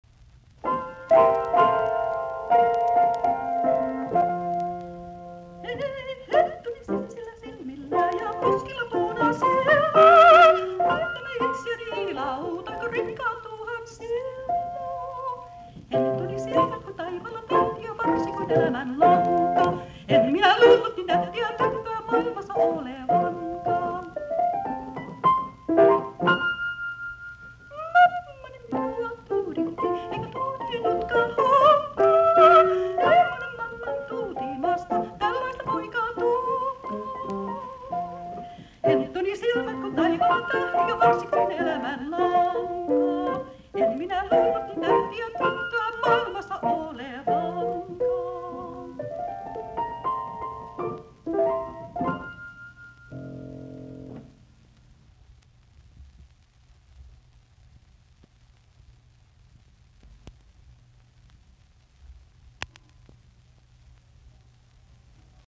musiikkiäänite